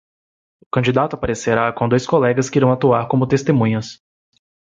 Pronunciado como (IPA)
/kɐ̃.d͡ʒiˈda.tu/